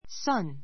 sʌ́n サ ン （ ⦣ sun （太陽）と同音）